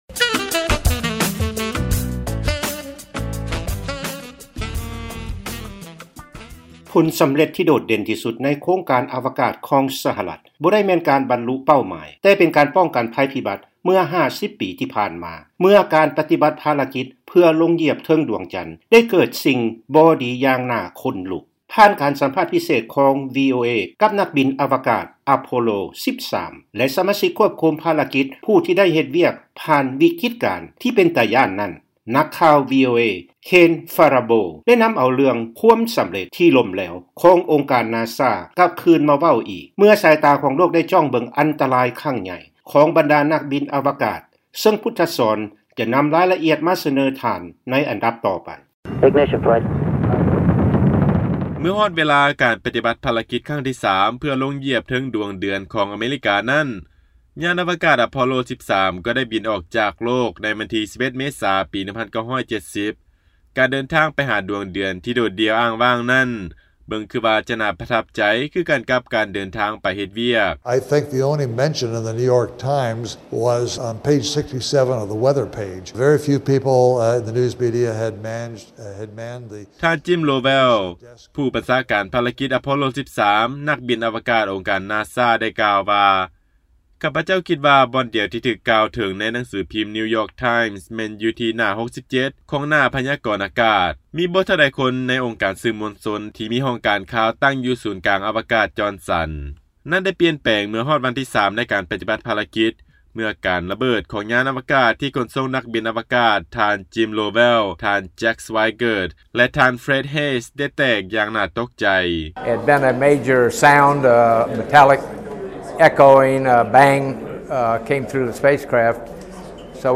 ຟັງລາຍງານ ພາລະກິດ ລົງຢຽບດວງເດືອນ Apollo 13 ເມື່ອ 50 ປີທີ່ຜ່ານມາ ໄດ້ກາຍເປັນ "ຄວາມສຳເລັດທີ່ລົ້ມແຫຼວ" ຂອງອົງການ NASA